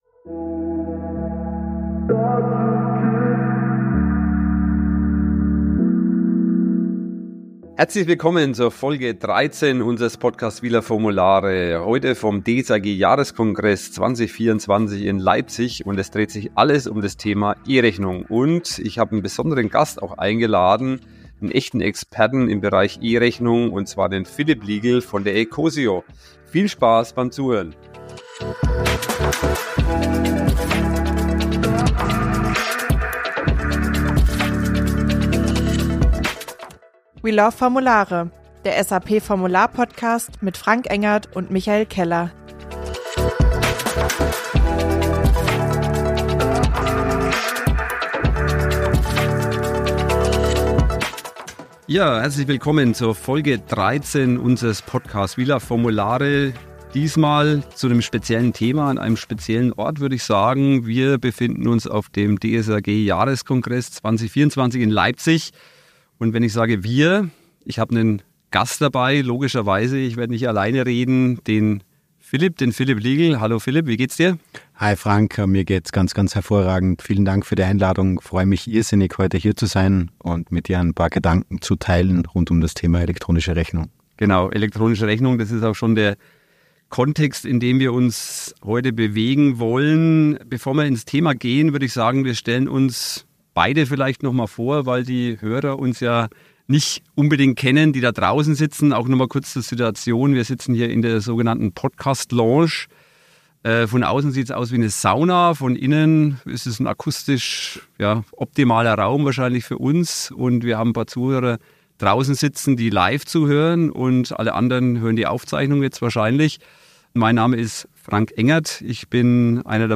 am DSAG Jahreskongress 2024.